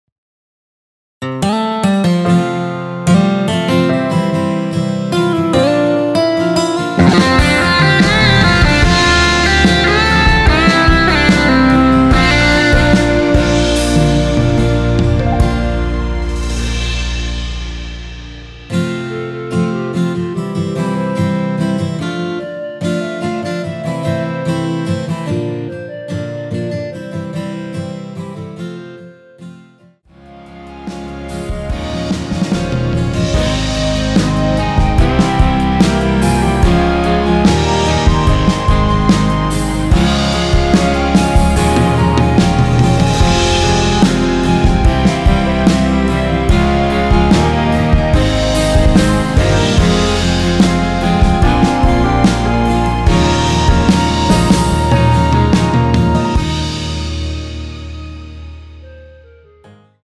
원키에서(-2)내린 (2절 삭제)멜로디 포함된 MR입니다.
앞부분30초, 뒷부분30초씩 편집해서 올려 드리고 있습니다.
중간에 음이 끈어지고 다시 나오는 이유는